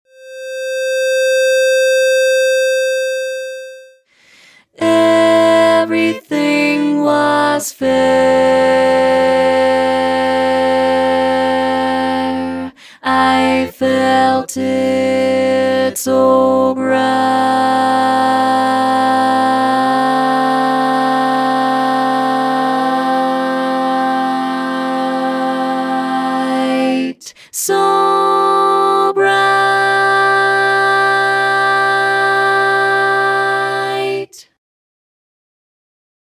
Key written in: C Major
Type: SATB